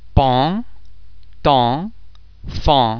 en em an am vowel-base similar to ong in (pong)
·[aon]
en_paon.mp3